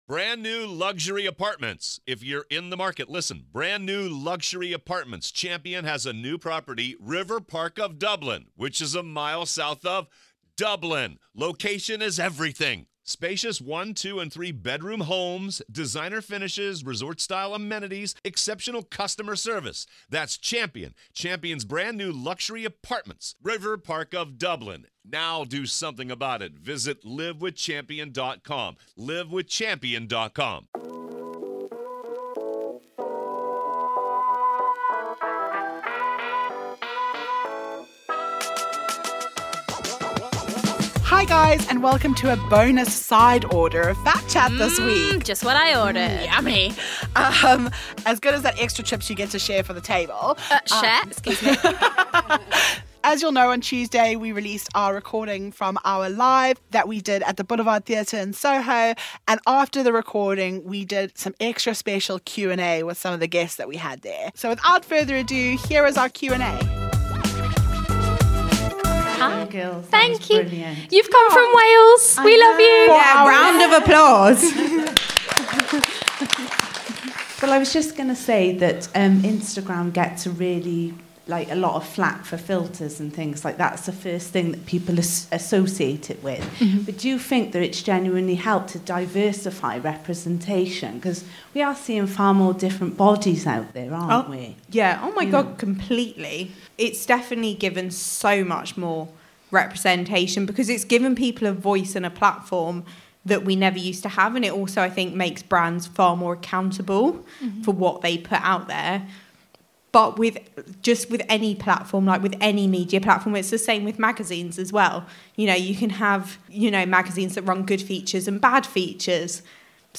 On Tuesday we released our podcast LIVE episode from The Boulevard Theatre in Soho, London. At the end we had a Q & A session before joining everyone in the bar and here it is!